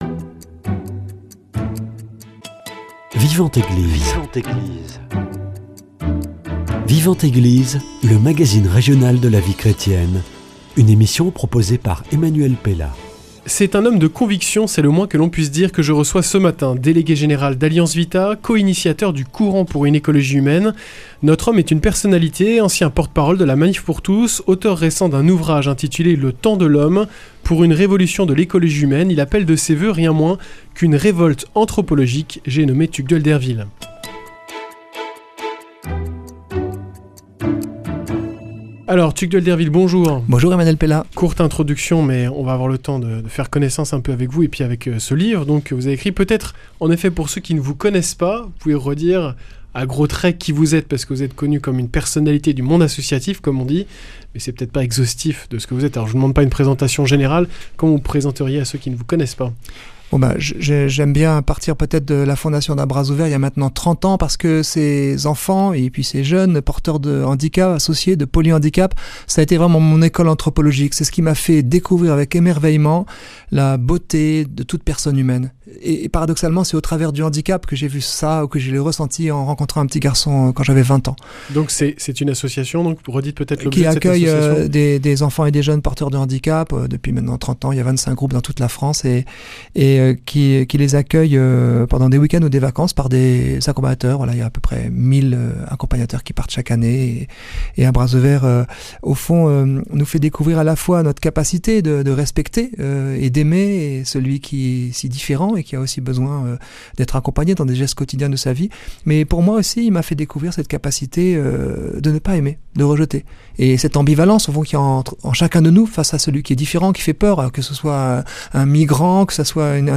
C’est un homme de conviction, c’est le moins que l’on puisse dire de l’invité de ce matin.